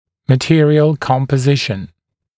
[mə’tɪərɪəl ˌkɔmpə’zɪʃn][мэ’тиэриэл ˌкомпэ’зишн]состав материала